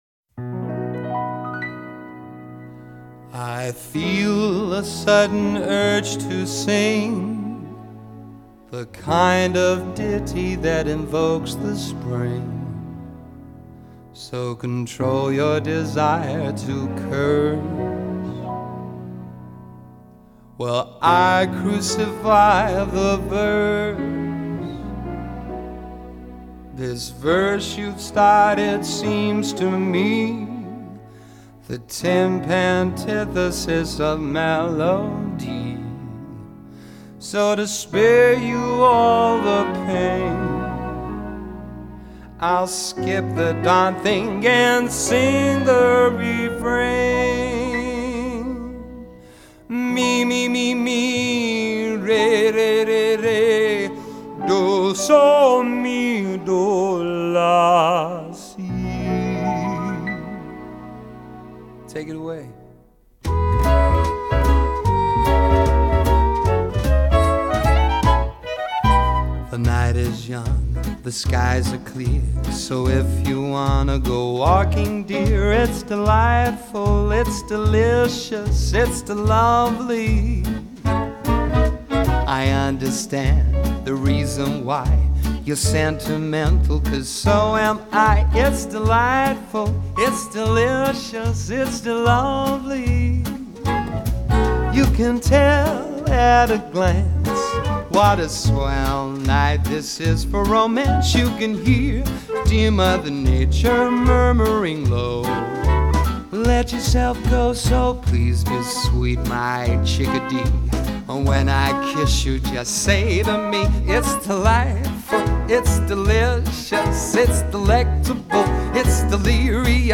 Genre: Musical